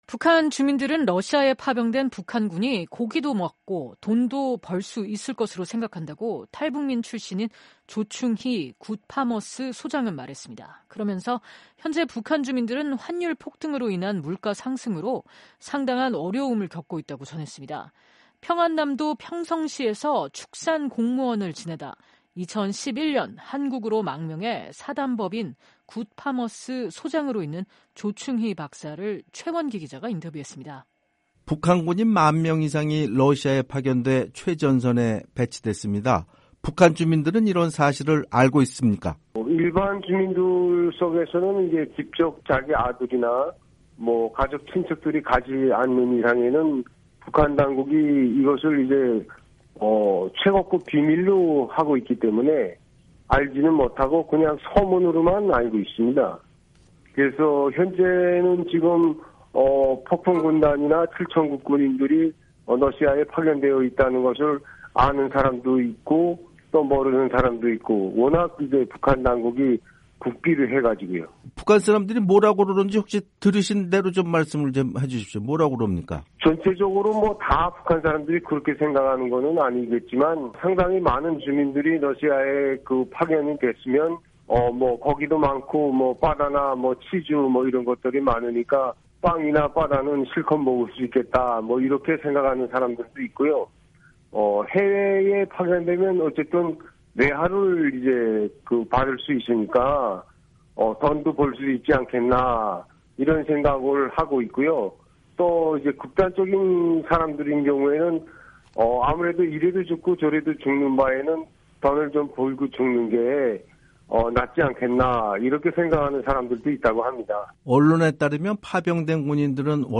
[인터뷰] 북한 주민들 “파병된 북한군 고기도 먹고 돈도 벌어”